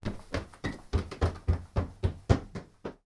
stairs1.mp3